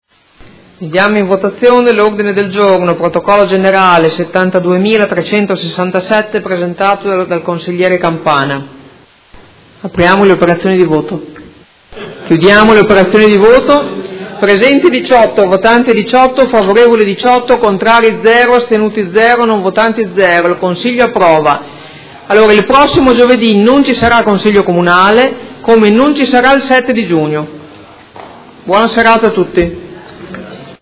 Presidente — Sito Audio Consiglio Comunale
Seduta del 17/05/2018. Mette ai voti Ordine del Giorno presentato dal Gruppo Articolo 1-MDP - PerMeModena avente per oggetto: Solidarietà al popolo palestinese e alle famiglie dei morti e feriti civili di Gaza, solo il 14 marzo 52 morti e oltre 2000 feriti e appello perché si fermi la violenza e venga rispettata la legalità internazionale.